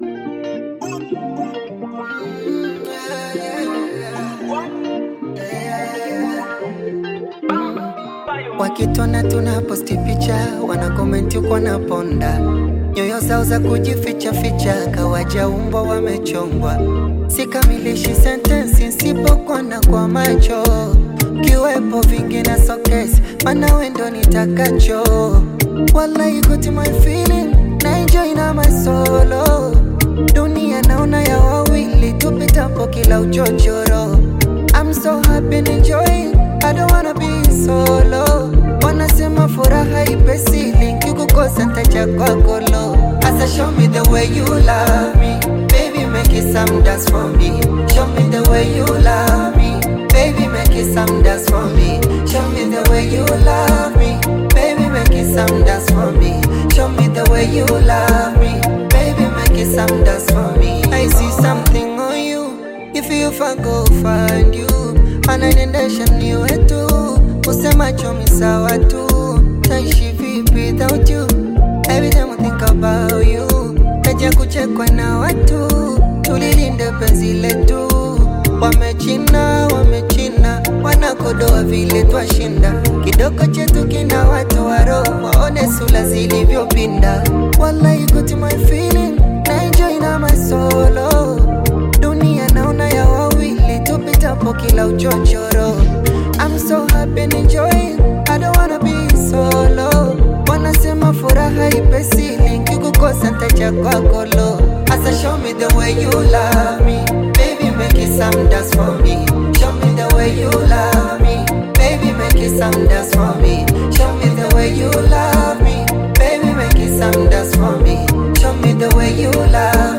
Bongo Flava music track
Bongo Flava